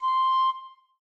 flute_c1.ogg